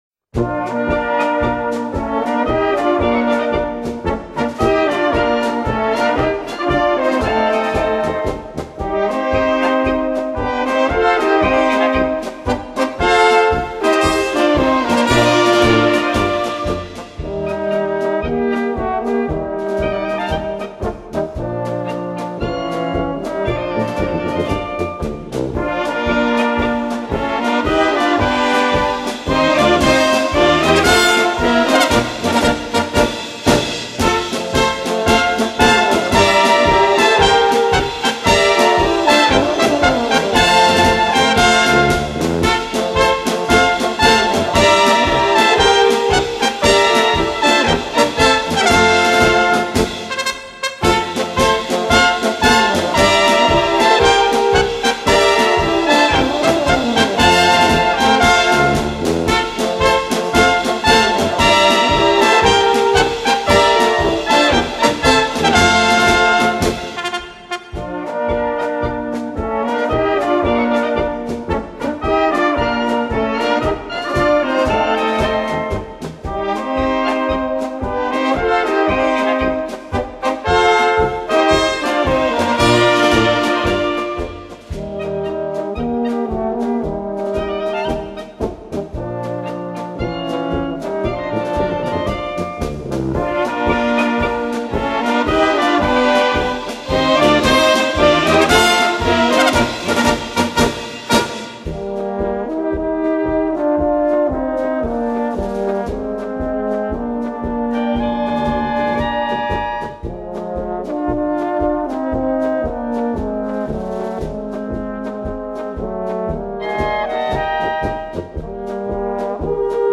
Gattung: für kleine Besetzung
Besetzung: Kleine Blasmusik-Besetzung
Eine tolle Polka, mit Pfiff und Schwung!
1x Flöte in C
2x 1. Flügelhorn in Bb
1x Schlagzeug